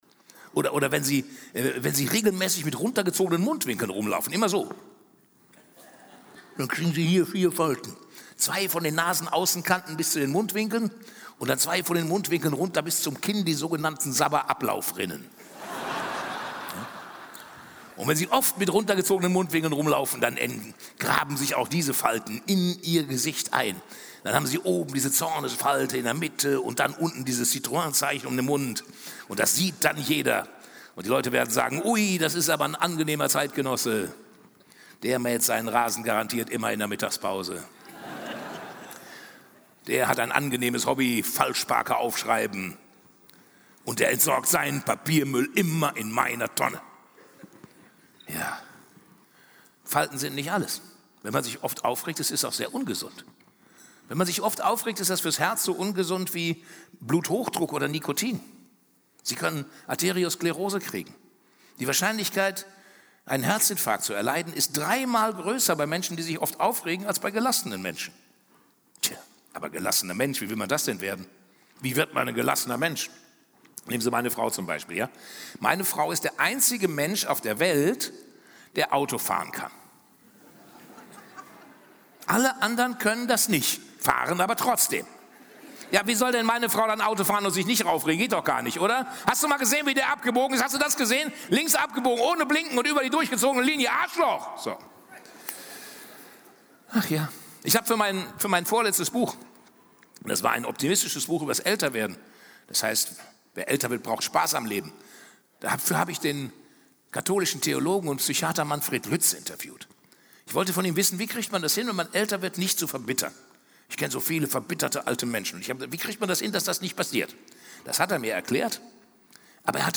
Hörbuch: Reg' Dich nicht auf.
Reg' Dich nicht auf. Gibt nur Falten! Live. Bernd Stelter (Autor) Bernd Stelter (Sprecher) Audio-CD 2025 | 1.